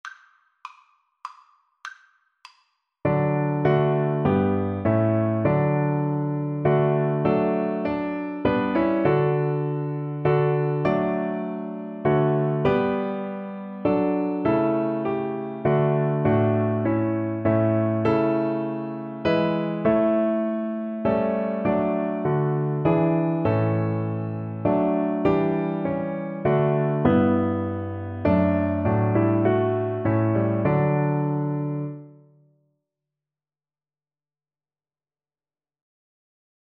3/4 (View more 3/4 Music)